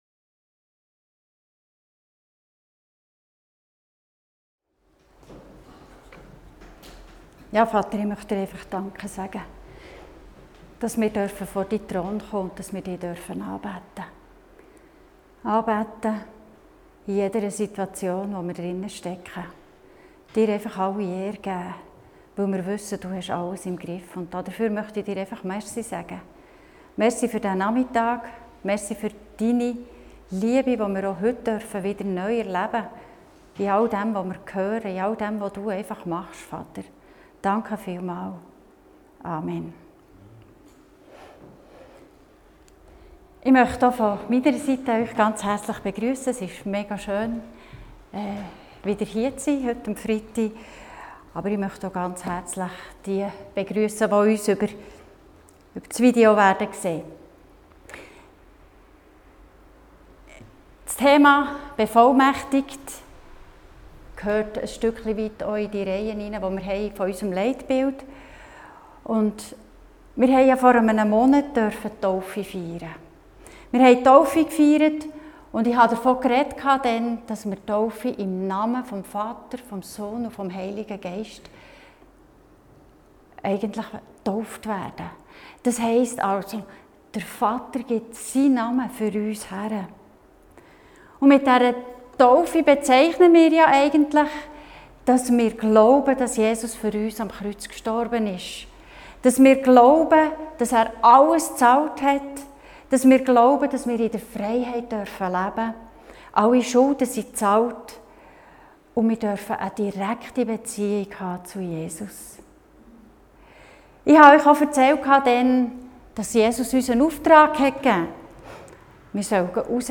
Dienstart: Gottesdienst